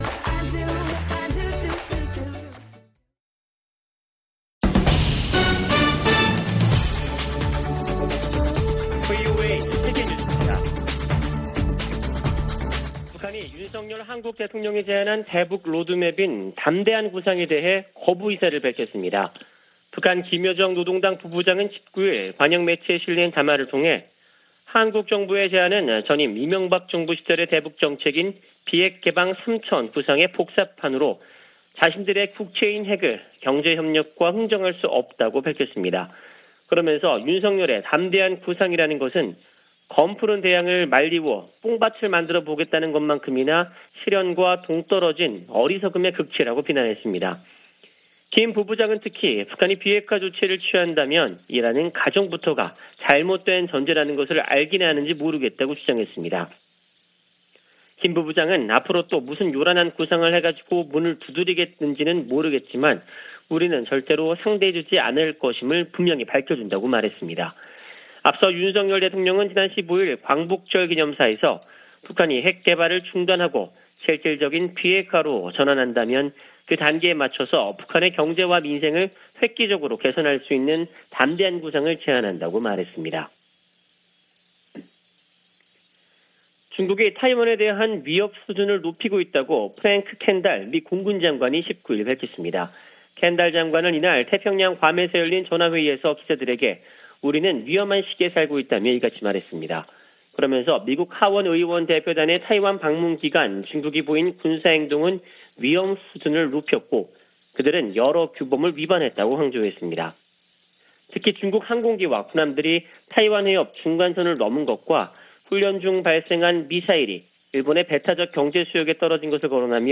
VOA 한국어 아침 뉴스 프로그램 '워싱턴 뉴스 광장' 2022년 8월 20일 방송입니다. 김여정 북한 노동당 부부장이 윤석열 한국 대통령의 '담대한 구상'을 정면 거부하는 담화를 냈습니다. 미 국무부는 북한이 대화 의지를 보이면 비핵화를 위한 점진적 단계가 시작되지만 이를 거부하고 있어 실질적 단계에 도입하지 못하고 있다고 밝혔습니다. 미국의 전문가들은 윤 한국 대통령의 한일 관계 개선 의지 표명을 긍정적으로 평가했습니다.